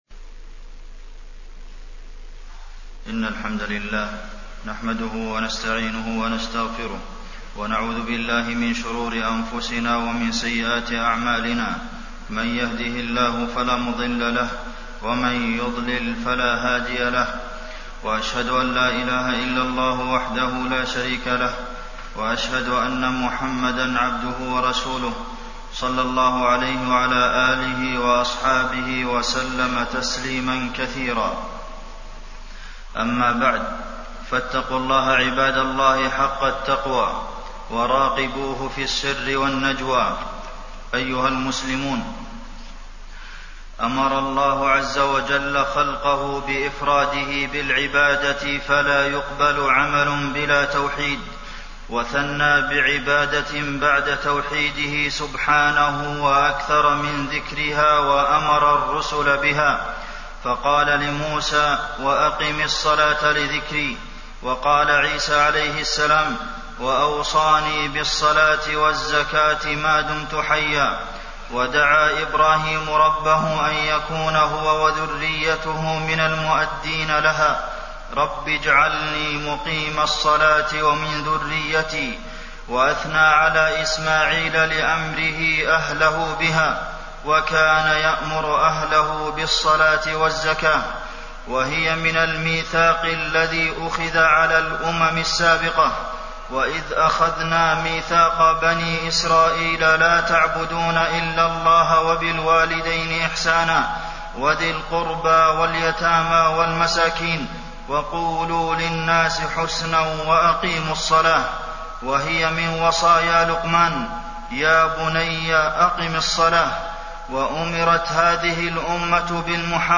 تاريخ النشر ٤ شعبان ١٤٣١ هـ المكان: المسجد النبوي الشيخ: فضيلة الشيخ د. عبدالمحسن بن محمد القاسم فضيلة الشيخ د. عبدالمحسن بن محمد القاسم الصلاة